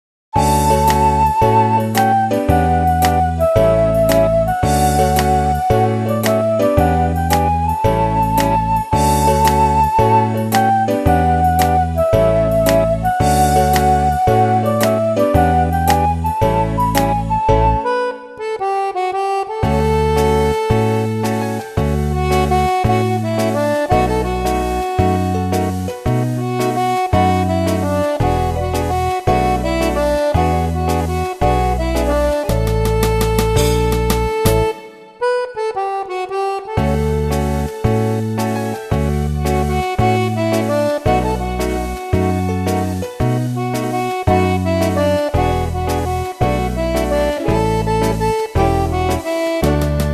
Slow fox-trot (28 bpm)
Fisa